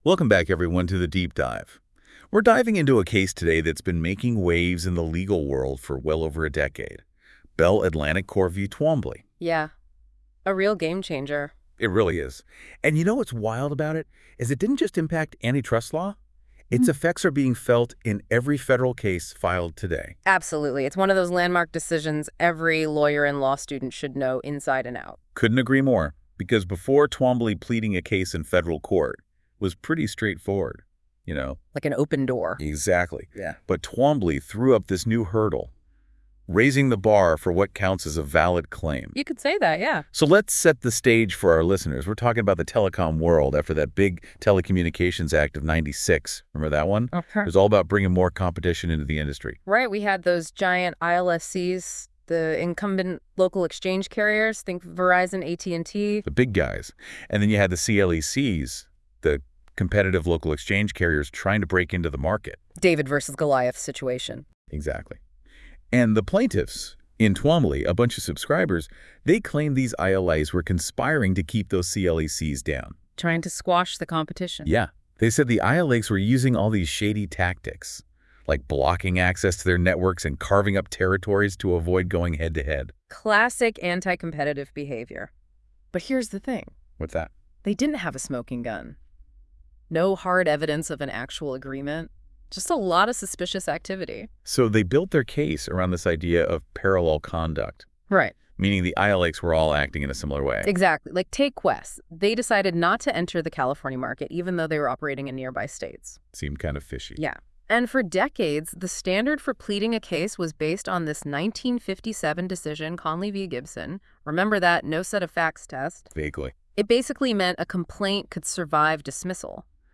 Listen to an audio breakdown of Bell Atlantic v. Twombly.